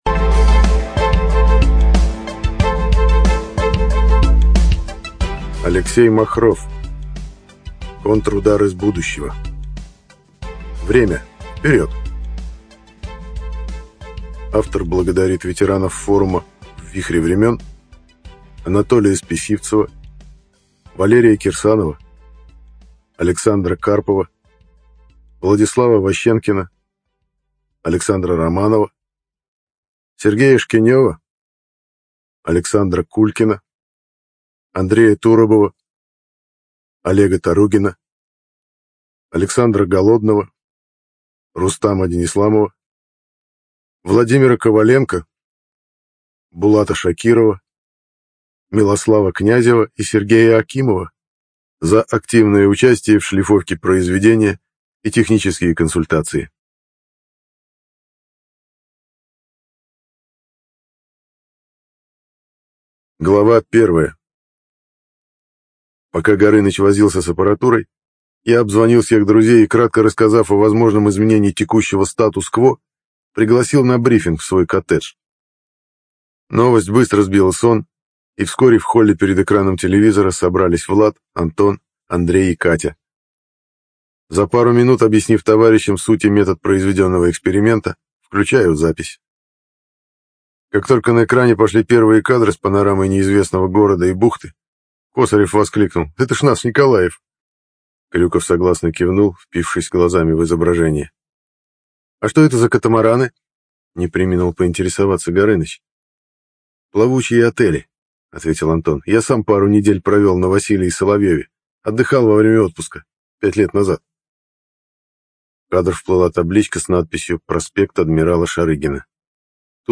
Жанр: Фантастика, исторические приключения, попаданцы